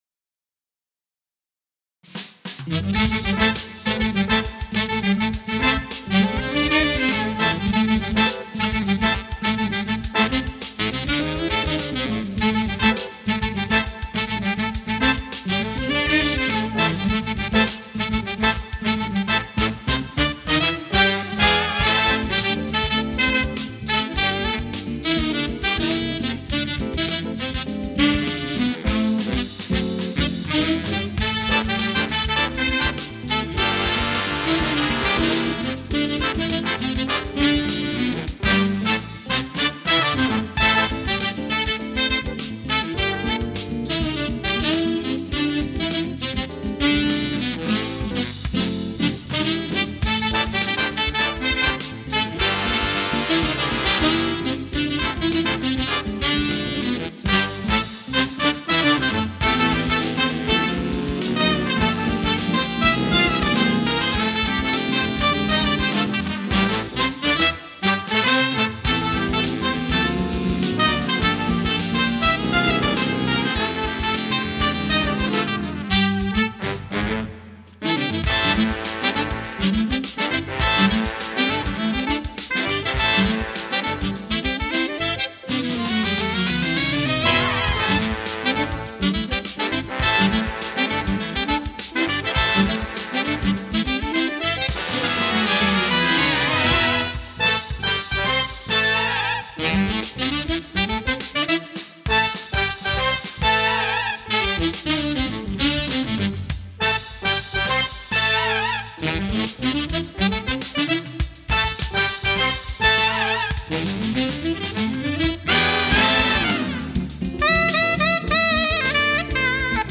is an advanced-level big band arrangement